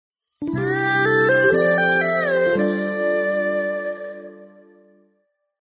Message tone 04.mp3